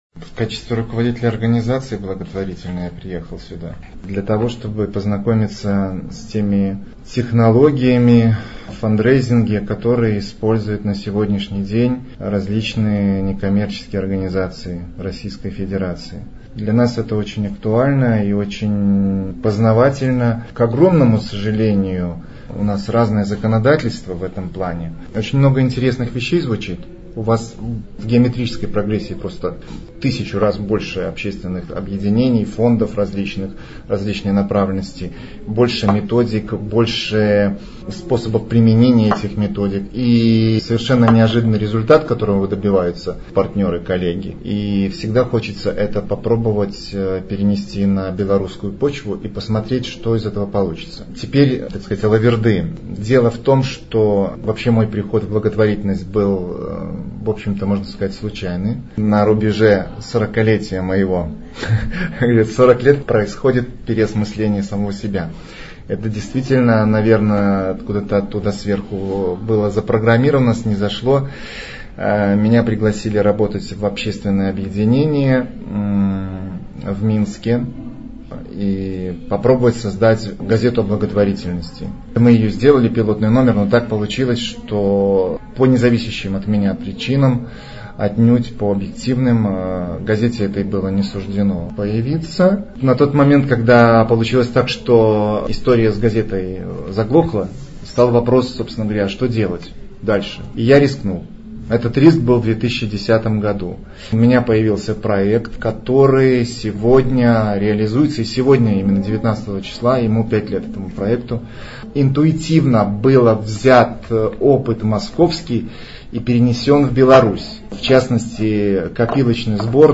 «Расскажем» — аудиопроект Агентства социальной информации: живые комментарии экспертов некоммерческого сектора на актуальные темы.